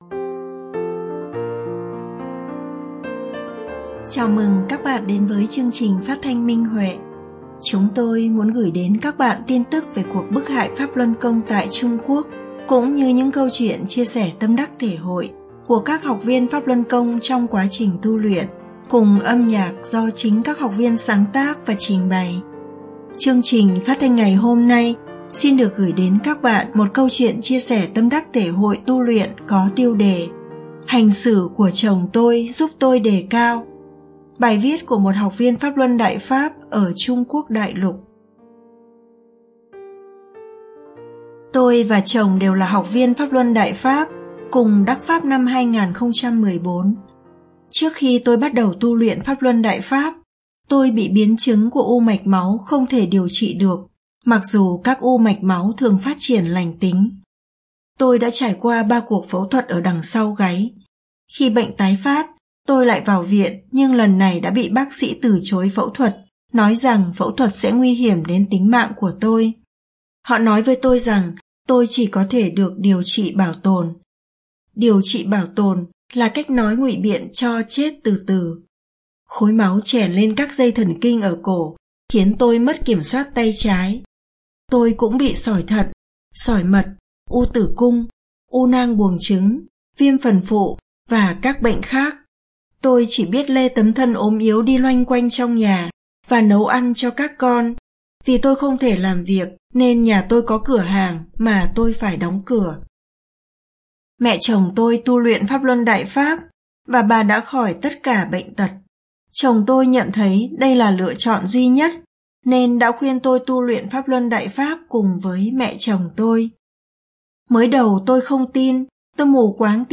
Chương trình phát thanh số 843: Bài viết chia sẻ tâm đắc thể hội trên Minh Huệ Net có tiêu đề Hành xử của chồng giúp tôi đề cao, bài viết của đệ tử Đại Pháp tại Trung Quốc.